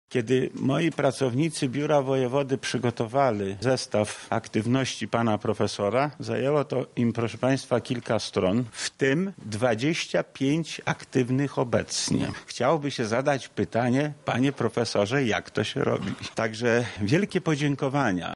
Jego wkład w rozwój regionu doceniły także lokalne władze, otrzymał odznaczenie, między innymi od Wojewody Lubelskiego Lecha Sprawki, który nie szczędził również słów uznania: